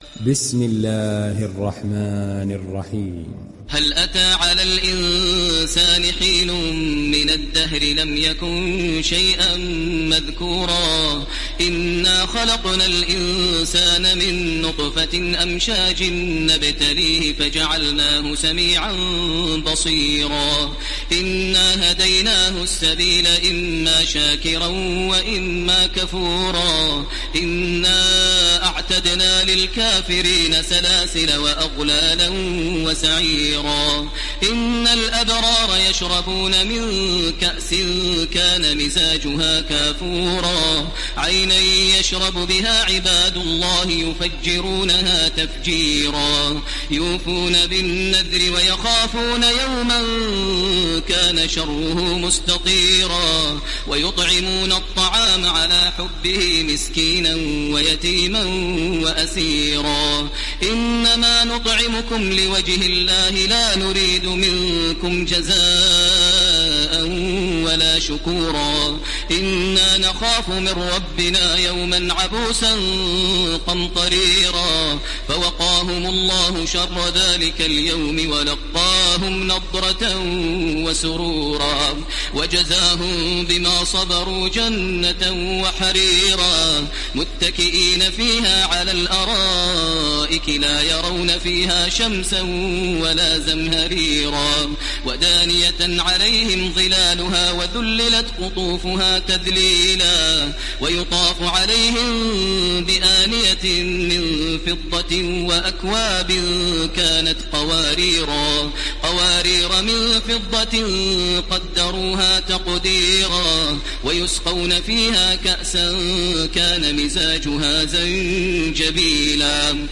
Download Surat Al Insan Taraweeh Makkah 1430